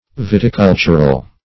Viticultural \Vit`i*cul"tur*al\